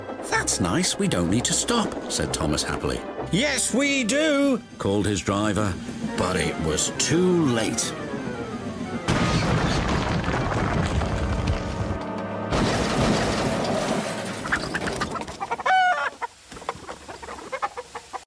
My favorite Thomas crash sound effects free download